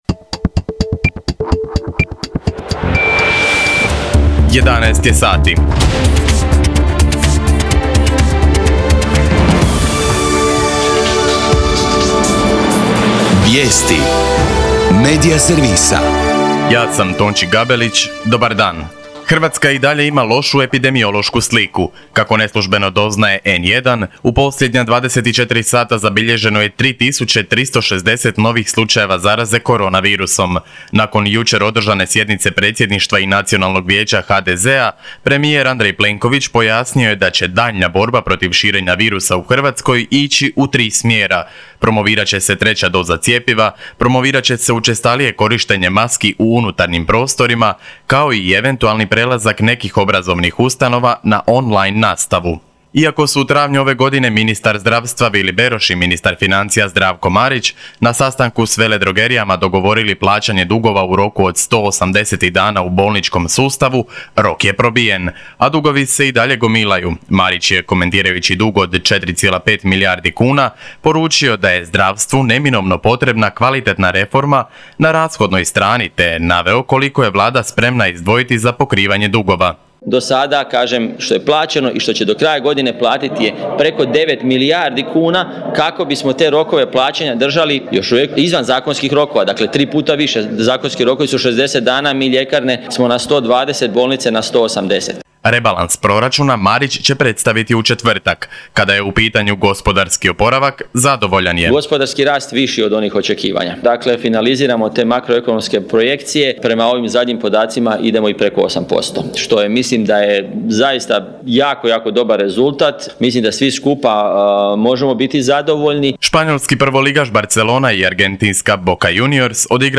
VIJESTI U 11